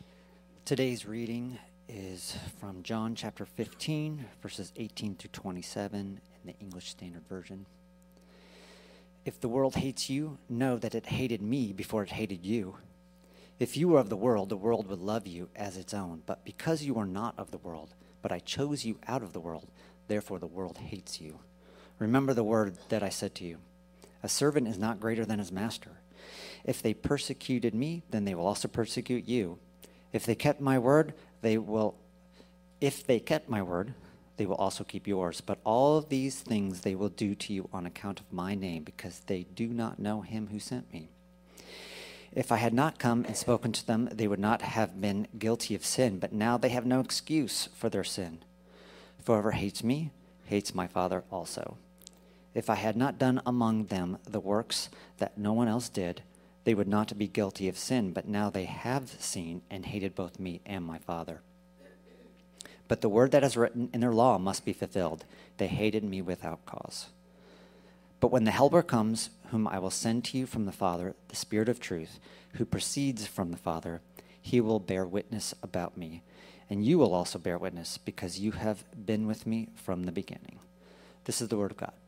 Read the message here: Endure John 15C